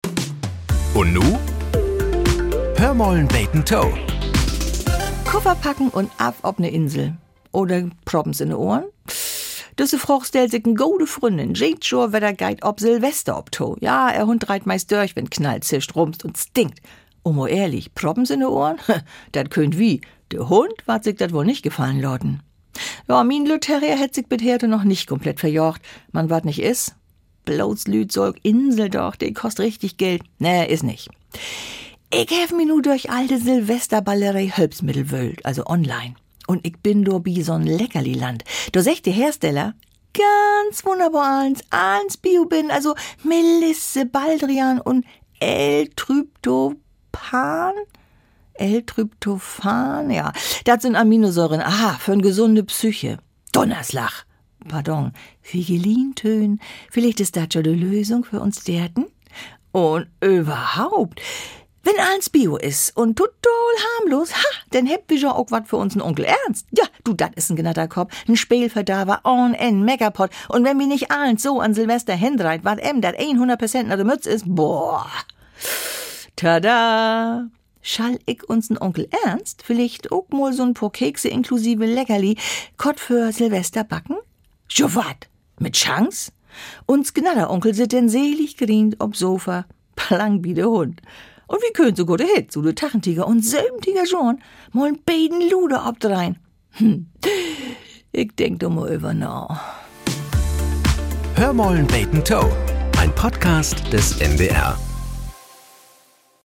Nachrichten - 19.02.2025